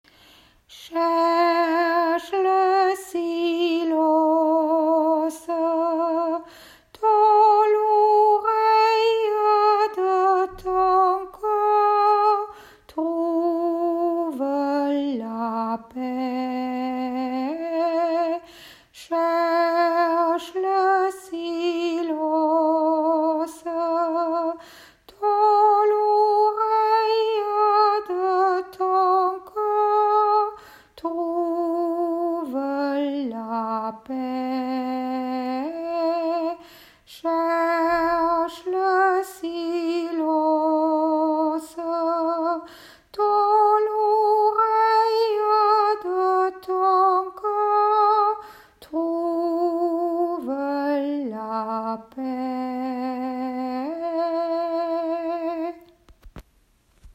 Chant méditatif « Cherche le silence »